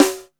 SPLAT.wav